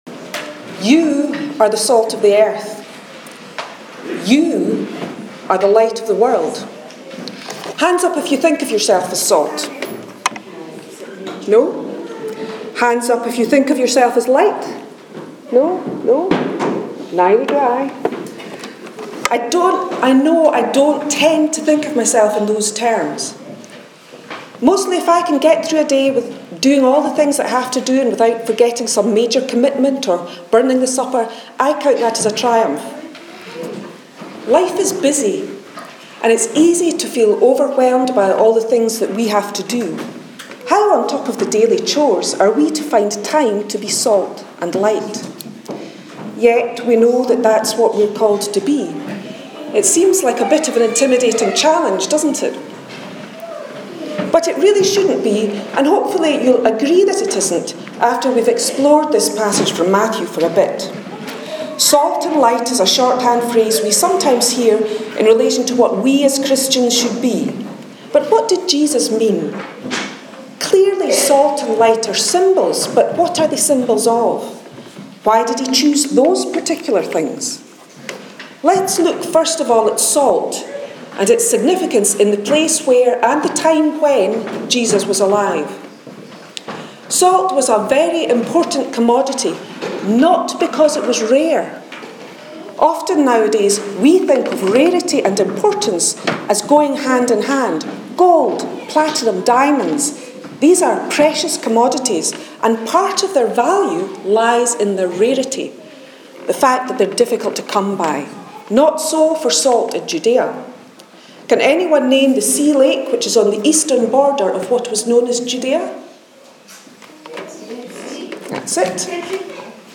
Message on Salt and Light- 5th November 2017
message_5th_november_2017.mp3